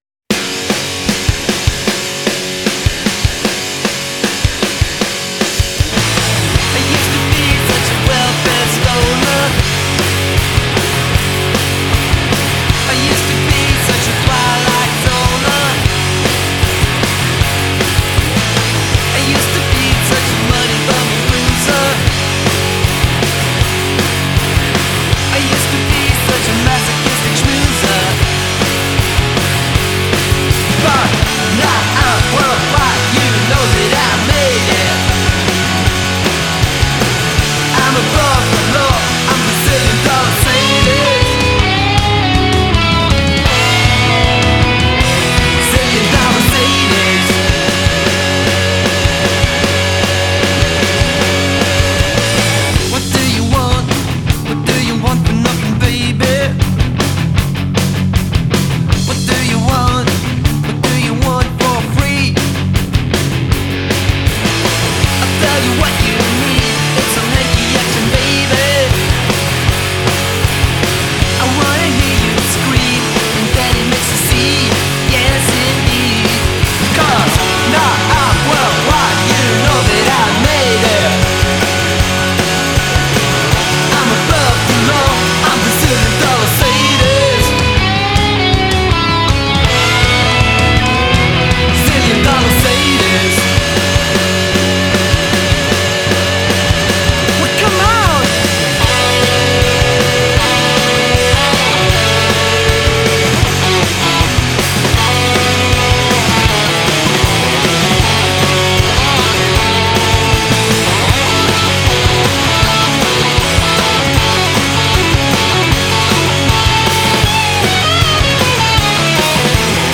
Жанр: hard rock, punk